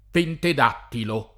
pented#ttilo] o Pentidattilo [